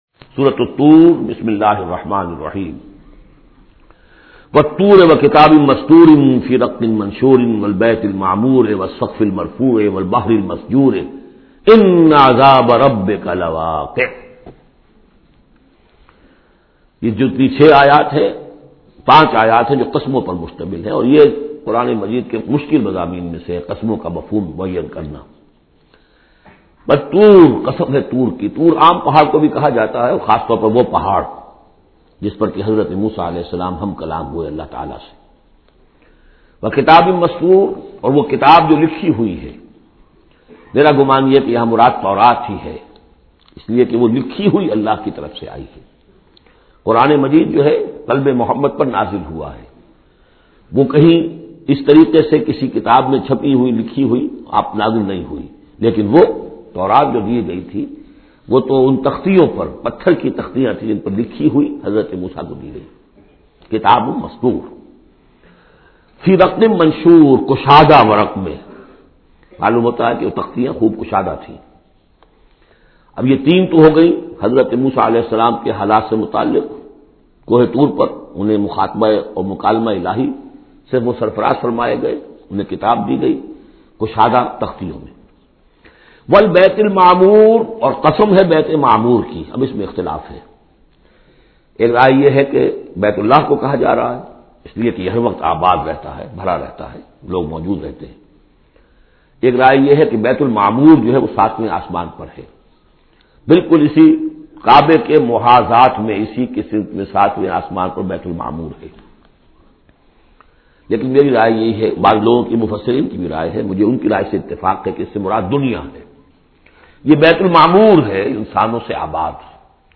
Surah Tur Tafseer by Dr Israr Ahmed
Surah Tur is 52nd chapter of Holy Quran. Listen online mp3 tafseer of Surah Tur in the voice of Dr Israr ahmed late.